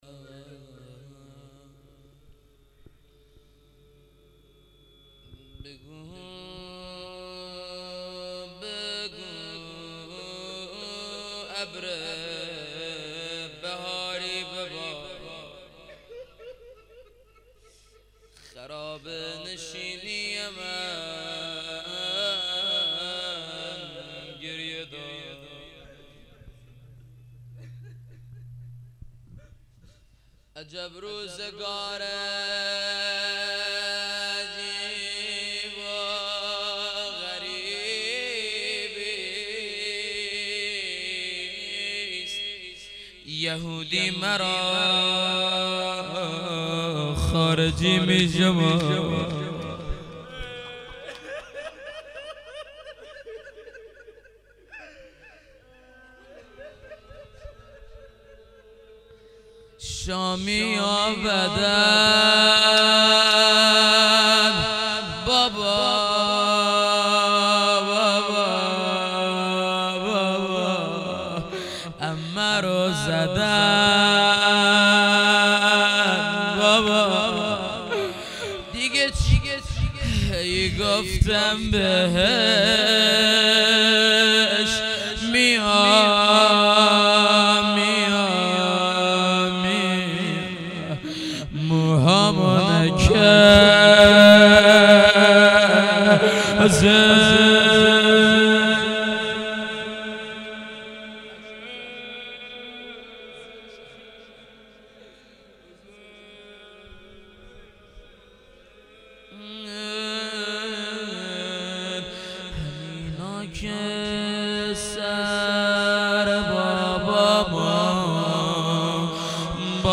heiat-levaolabbassham-shahadat-hazrat-roghaieh-rozeh.mp3